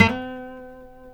C#4 HAMRNYL.wav